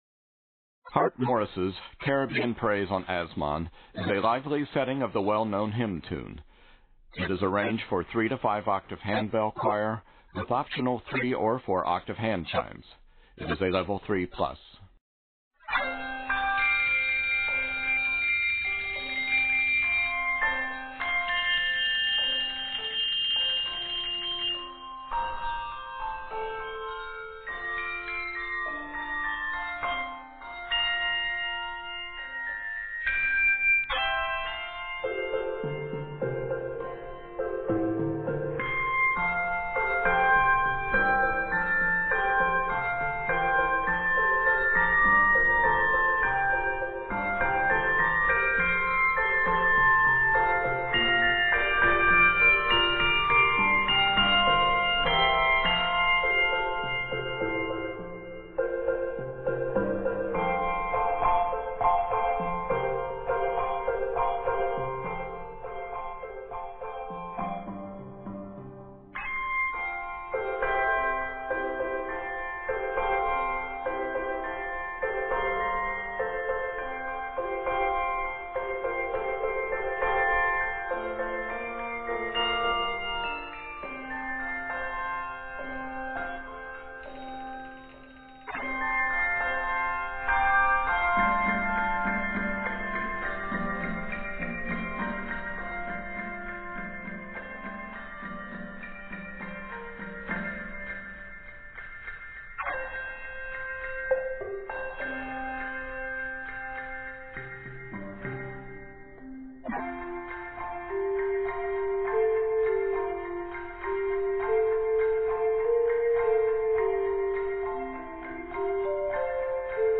fun, syncopated rhythms that speak of exotic islands
strong, full, expressions of joy
Octaves: 3-5